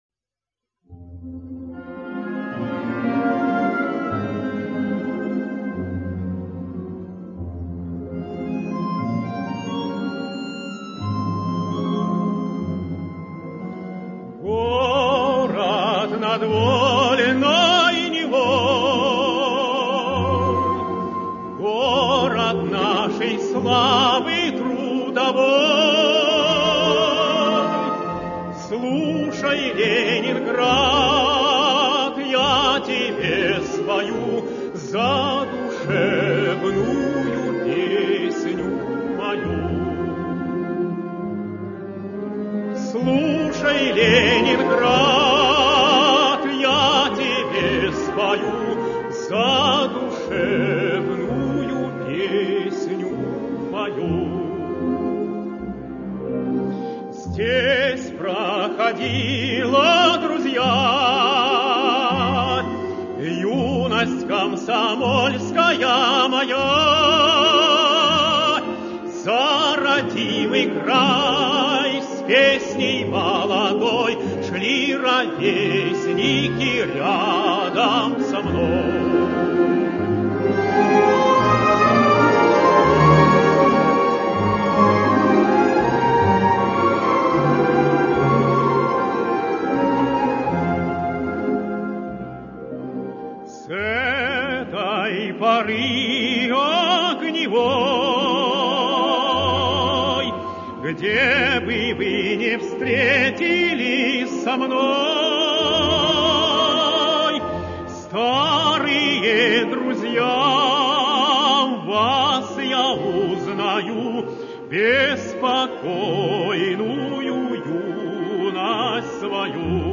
оркестр.